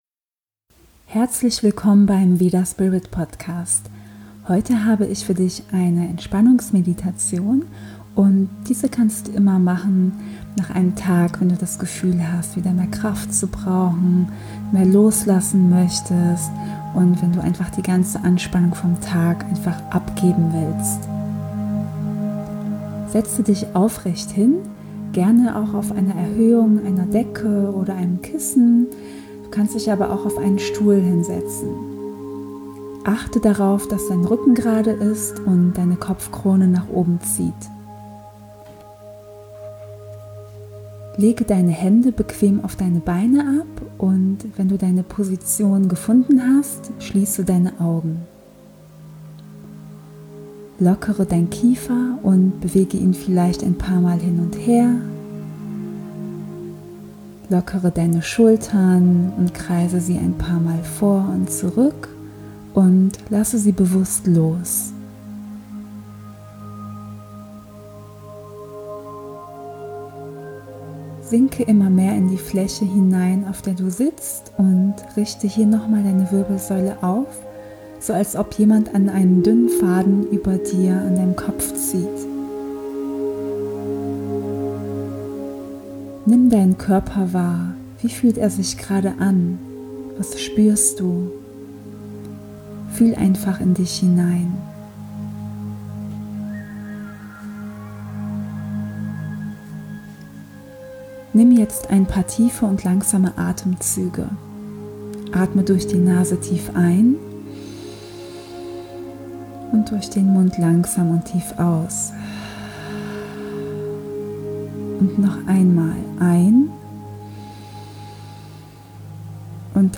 8# Entspannungs Meditation